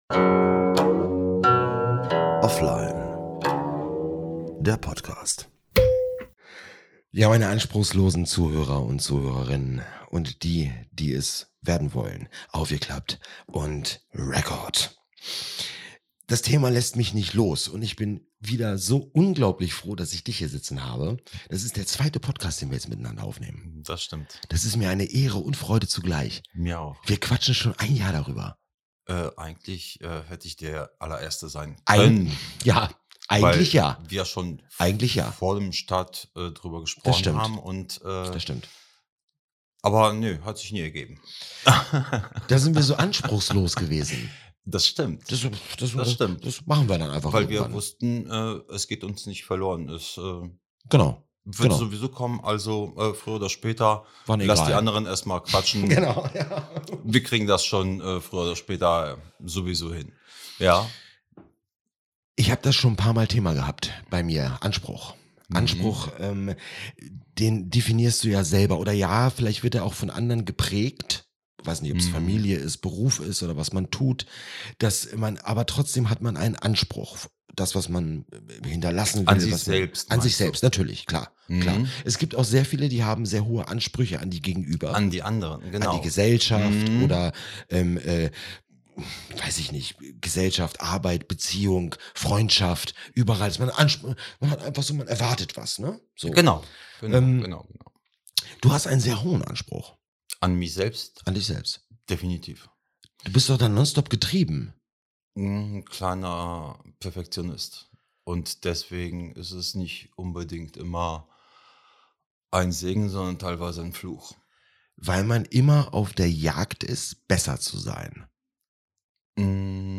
Man(n) will ja immer besser sein als die Latte die man in der Vergangenheit gesetzt hat. Zu Gast der beste Freund mit einem Schwätzerchen über den Eigenanspruch